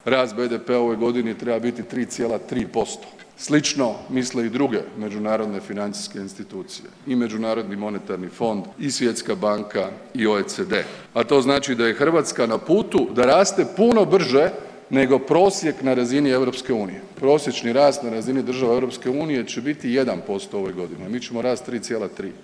ZAGREB - Mandatar Andrej Plenković u Saboru predstavlja program nove vlade, a na njegovo predstavljanje prijavljen je rekordan broj zastupničkih replika.